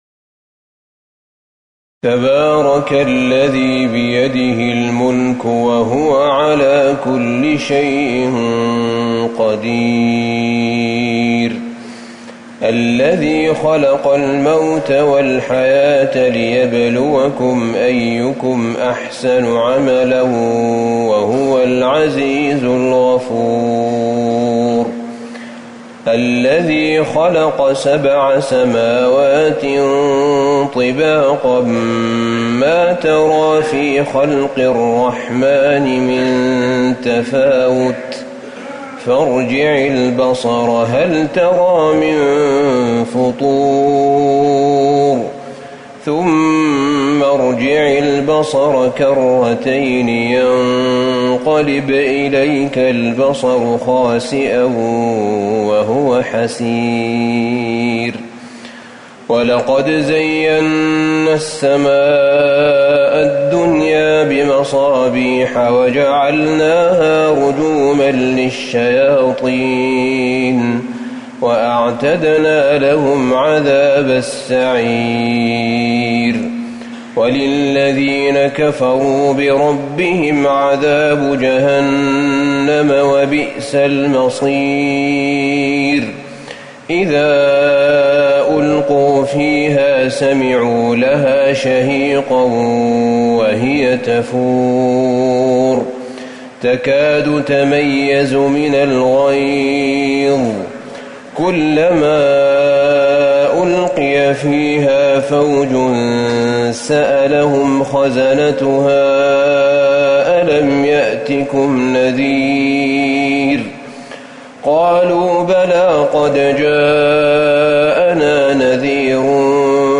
صلاة الفجر ١٤٤١/١/٨ سورة الملك | Fajr prayer from Surat Al-Mulk > 1441 🕌 > الفروض - تلاوات الحرمين